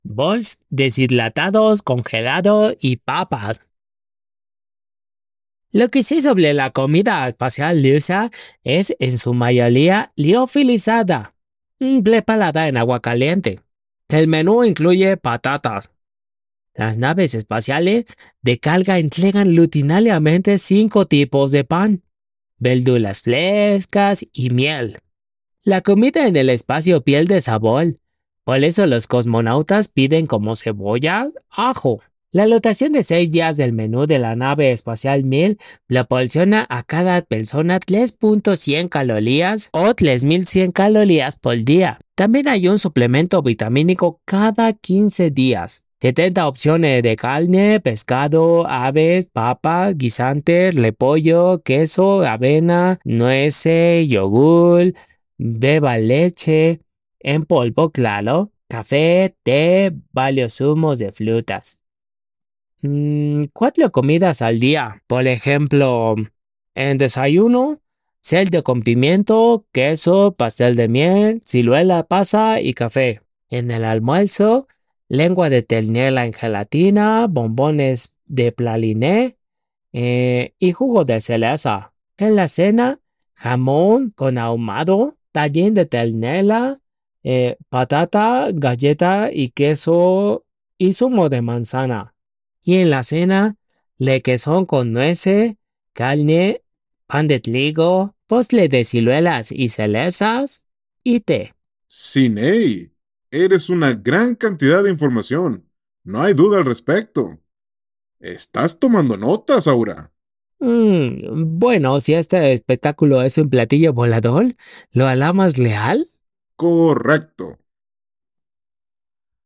Suba también el auricular y escuche una ronda de aplausos.